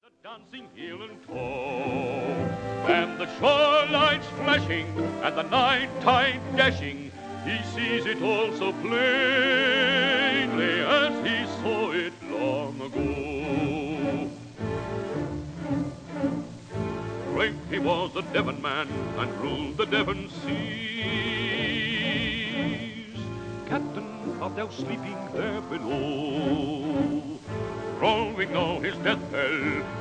Australian baritone